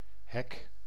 Ääntäminen
IPA: /ɦɛk/